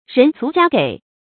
人足家給 注音： ㄖㄣˊ ㄗㄨˊ ㄐㄧㄚ ㄐㄧˇ 讀音讀法： 意思解釋： 同「人給家足」。